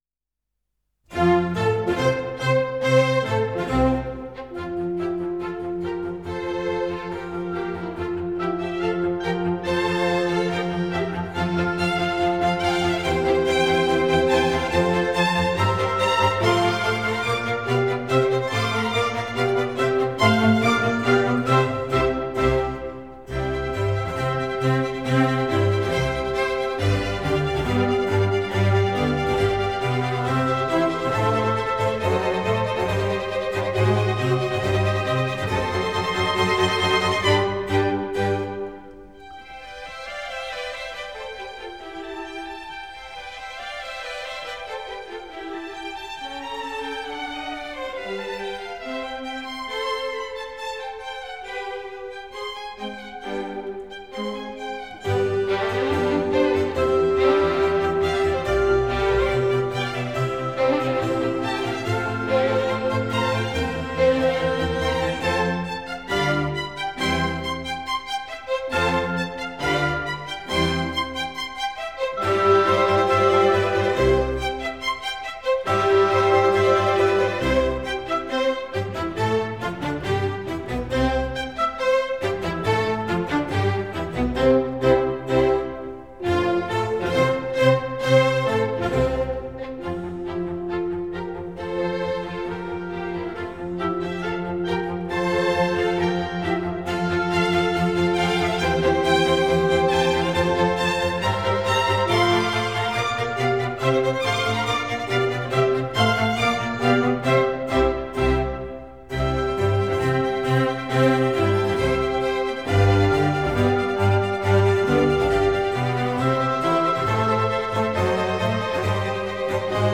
» 1 - Symphonies